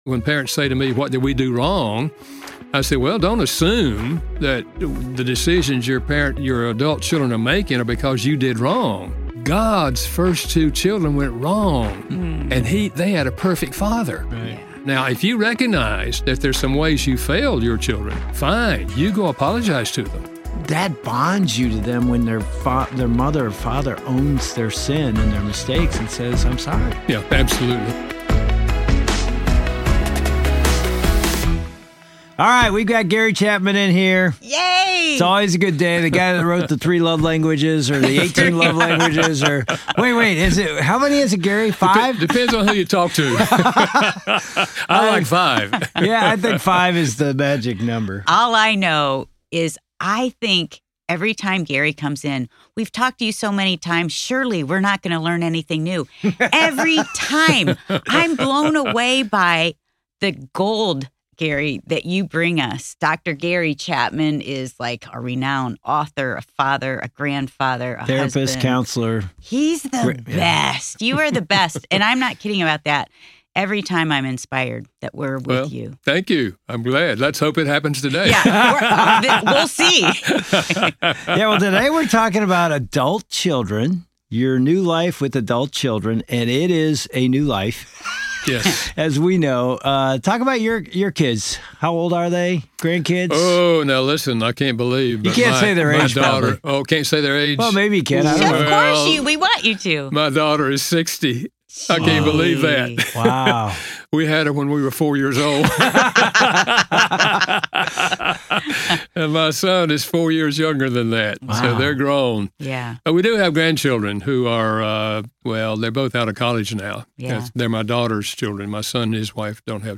chat with Dr. Gary Chapman, author of The Five Love Languages, on this episode of FamilyLife Today, offering vital wisdom for parents of adult children. Learn how to release them to make their own choices, even when it's difficult, and maintain a loving influence through open communication.